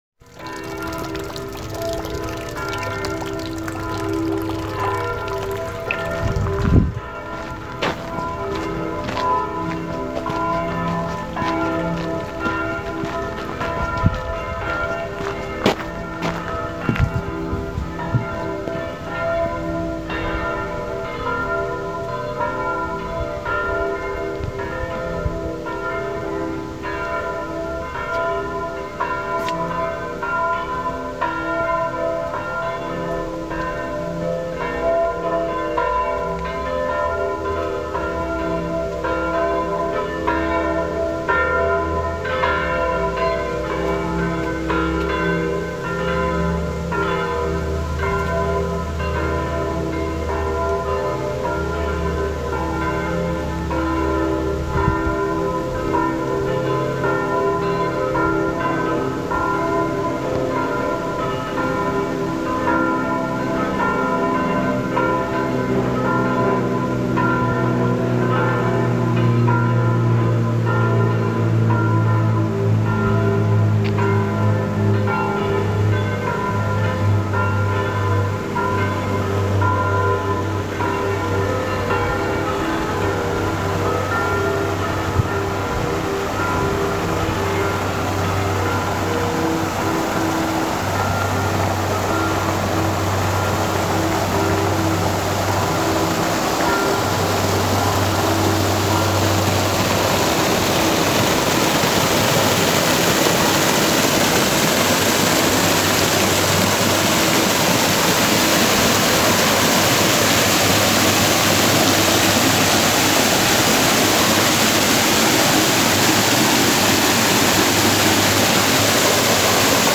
Bells and waterfall in Padova
Walking through the botanic gardens of Padova, we walk beneath a constructed waterfall at the same time as cathedral bells ring beneath us - an unusual confluence of water and bell sounds.